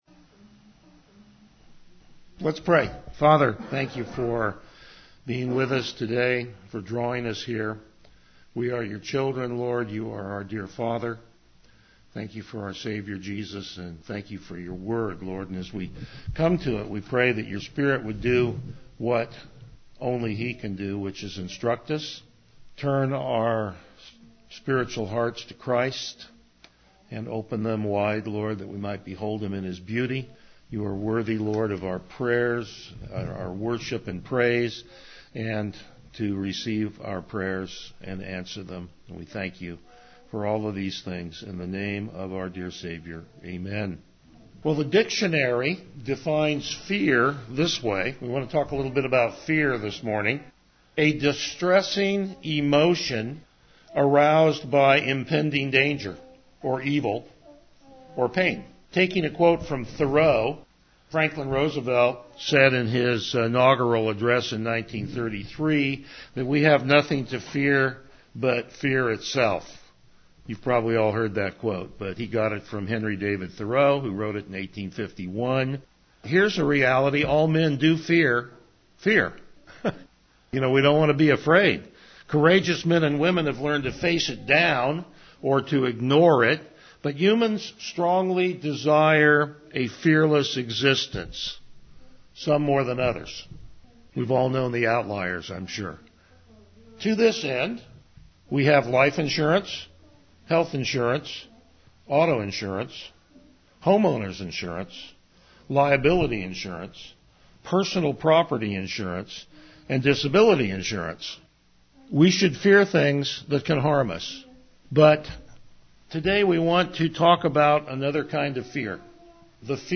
Passage: Hebrews 3:15 - 4:11 Service Type: Morning Worship